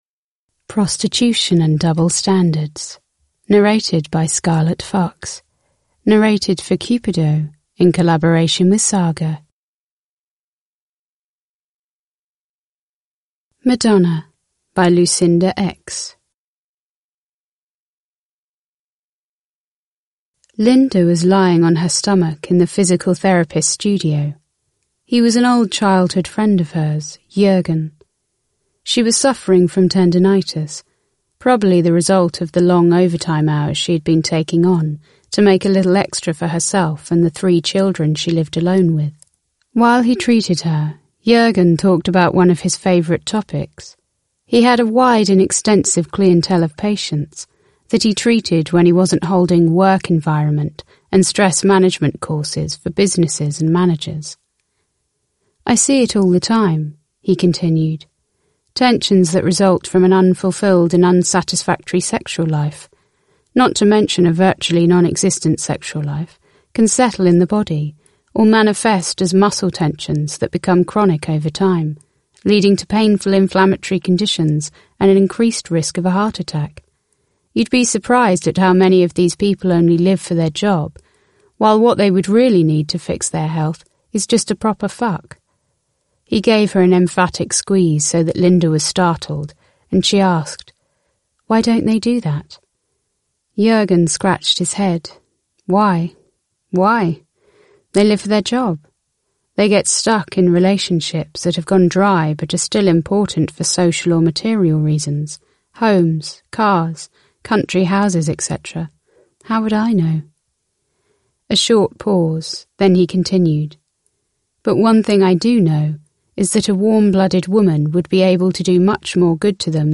Prostitution and double standards (ljudbok) av Cupido